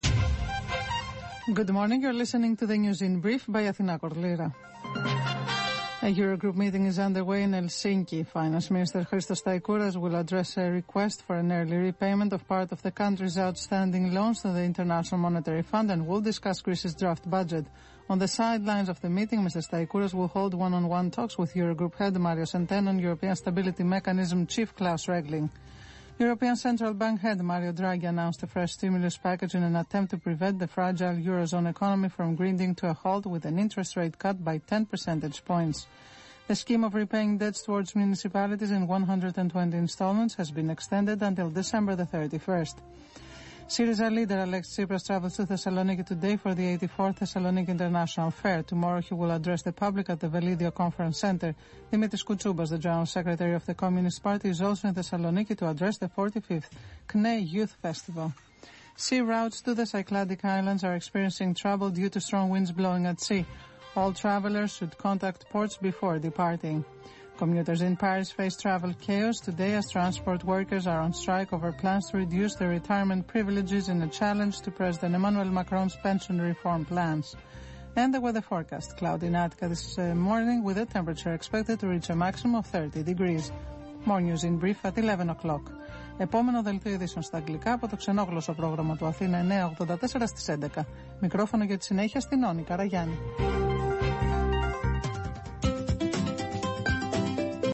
10.00 News in brief